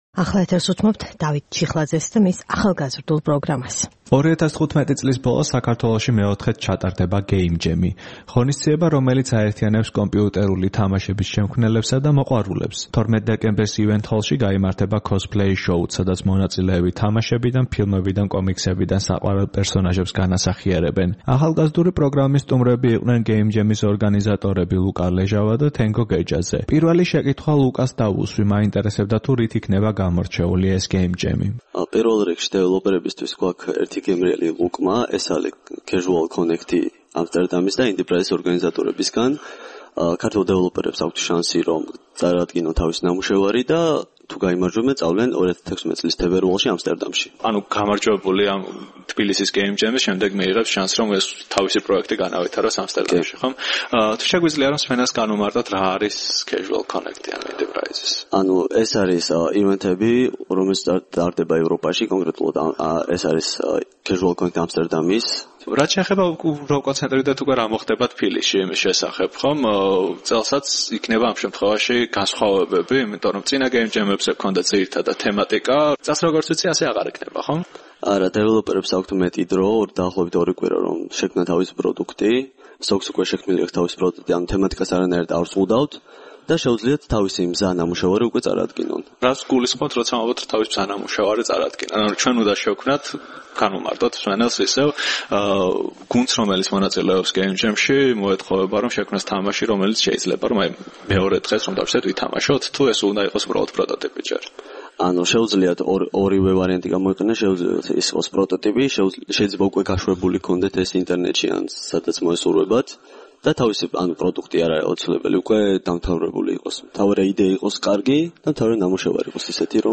კომიქსებიდან საყვარელ პერსონაჟებს განასახიერებენ. ახალგაზრდული პროგრამის სტუმრები იყვნენ გეიმჯემის ორგანიზატორები